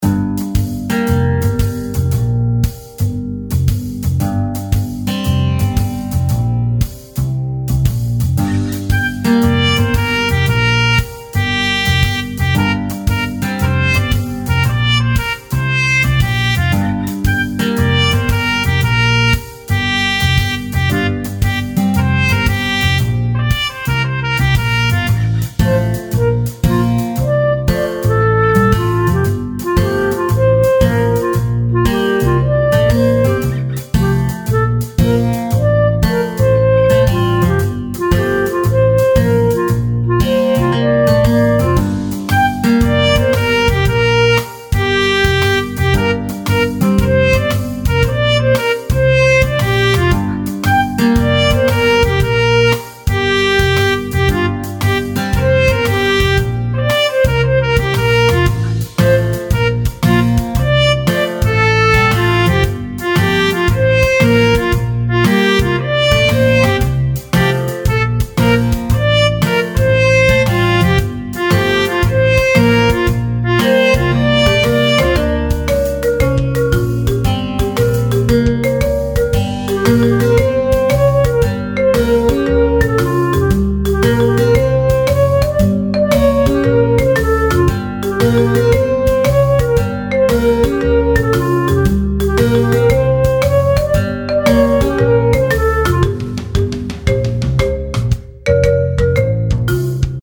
rofl)怎麼有爵士酒吧的FU
介紹→ファンタジー系。